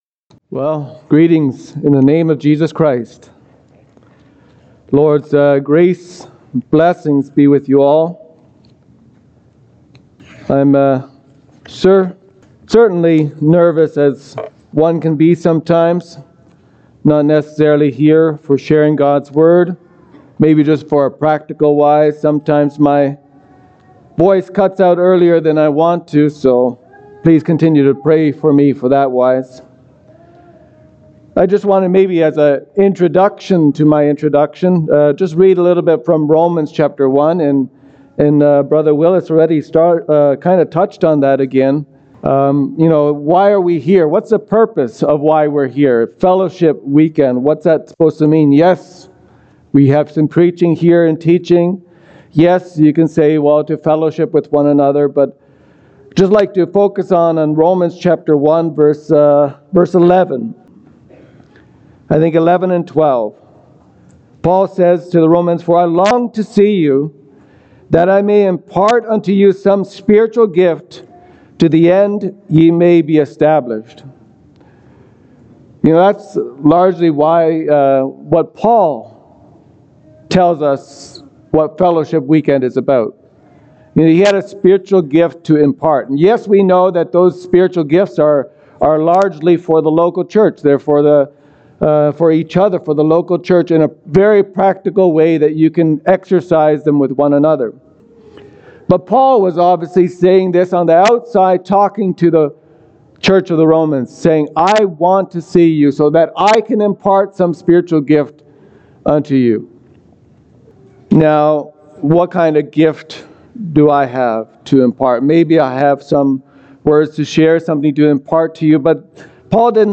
Saturday Evening Message